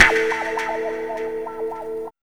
2806L GTRCHD.wav